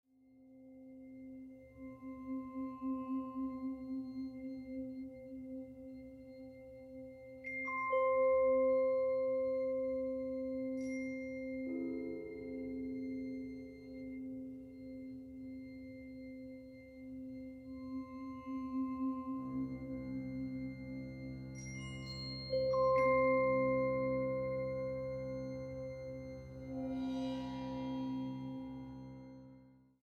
ópera infantil